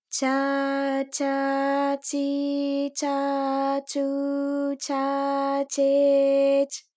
ALYS-DB-001-JPN - First Japanese UTAU vocal library of ALYS.
cha_cha_chi_cha_chu_cha_che_ch.wav